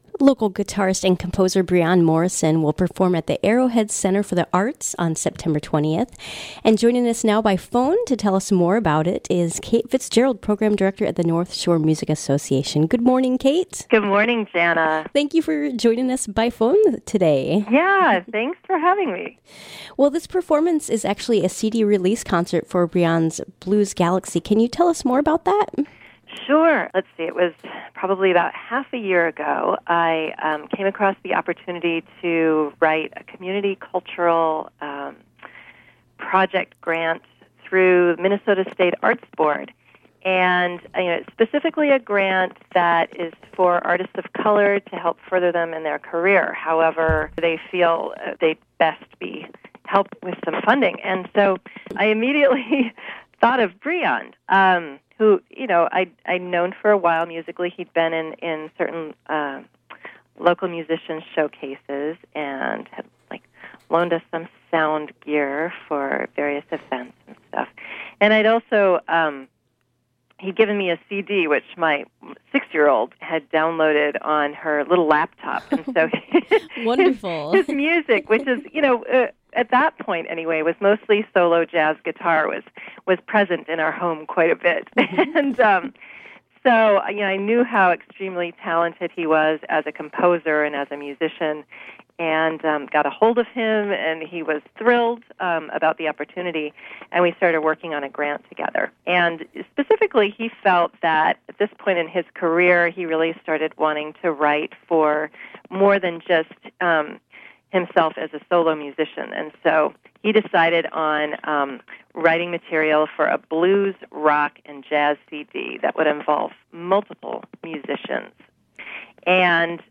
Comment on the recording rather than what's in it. WTIP North Shore Community Radio, Cook County, Minnesota